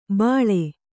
♪ bāḷe